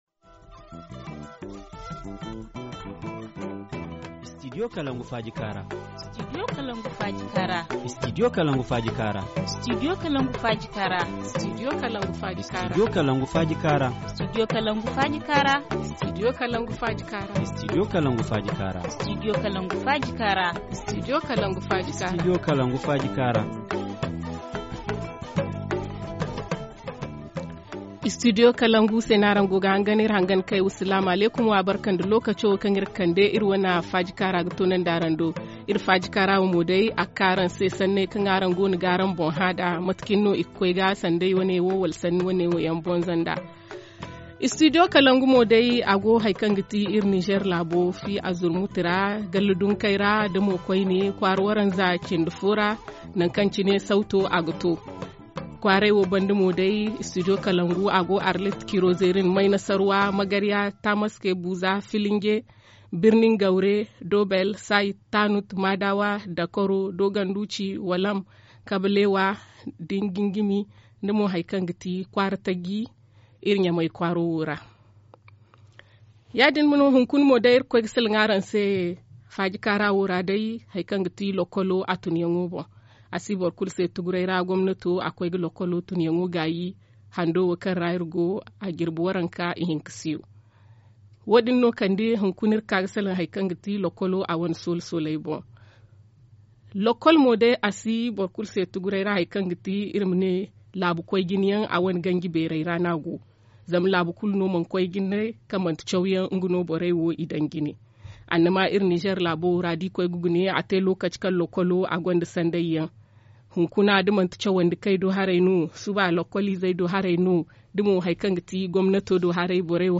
Forum en Zarma https